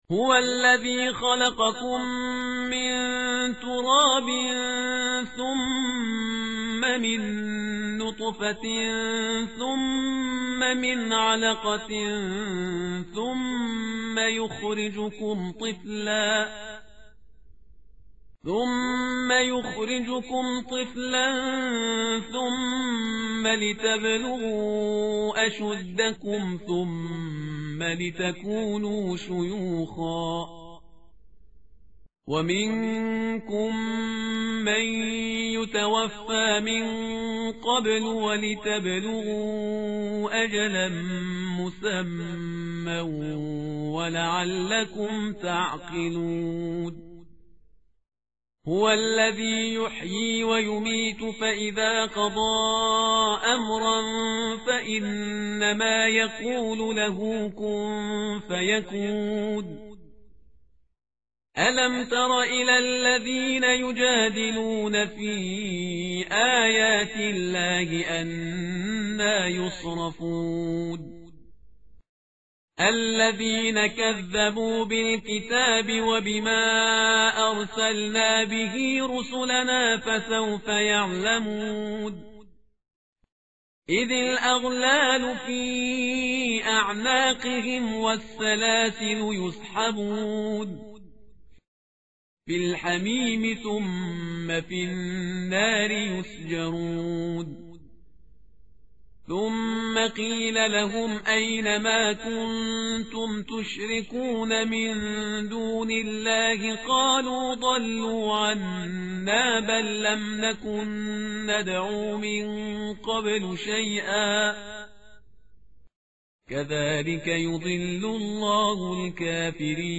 tartil_parhizgar_page_475.mp3